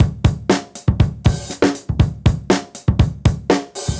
Index of /musicradar/dusty-funk-samples/Beats/120bpm
DF_BeatD_120-04.wav